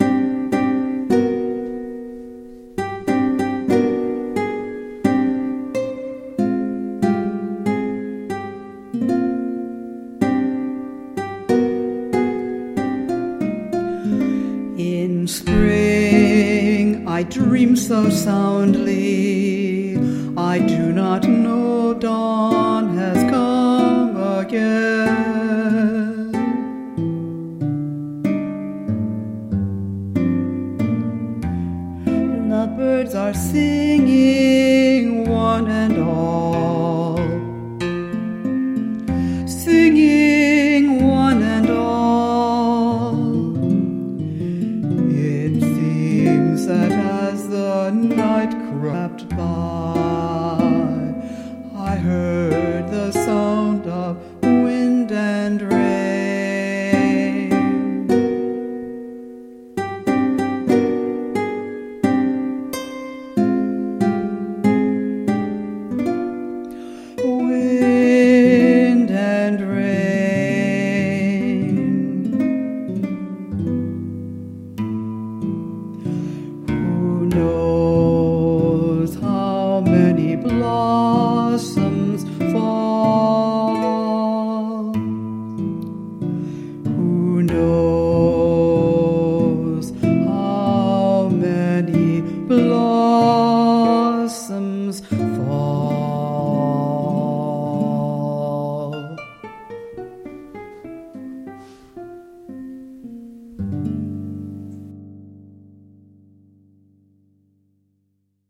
for solo voice and guitar
for solo voice and piano